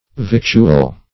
Victual \Vict"ual\ (v[i^]t"'l), n.